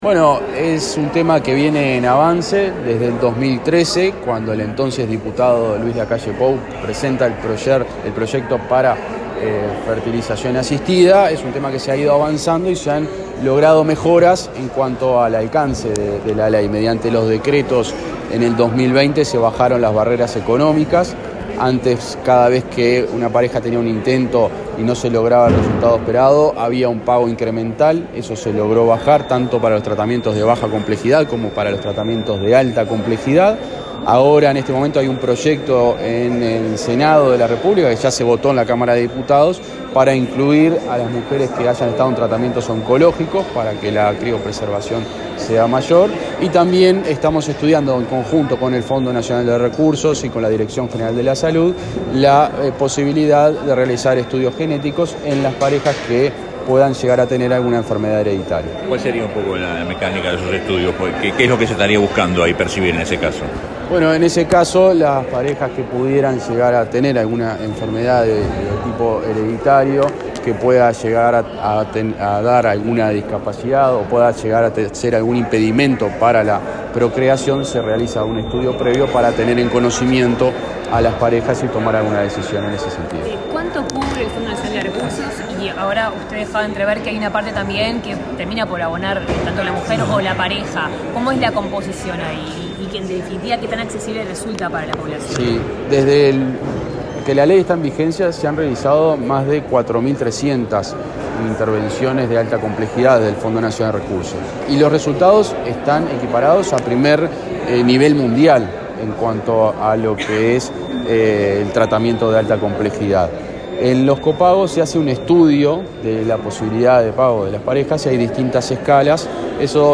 Declaraciones a la prensa de autoridades de Salud Pública
Declaraciones a la prensa de autoridades de Salud Pública 03/06/2022 Compartir Facebook X Copiar enlace WhatsApp LinkedIn Luego del acto de lanzamiento de la cuarta edición de la Semana de la Fertilidad, realizado este viernes 3 en Torre Ejecutiva, el ministro interino, José Luis Satdjian, y el director general de Salud, Miguel Asqueta, dialogaron con la prensa.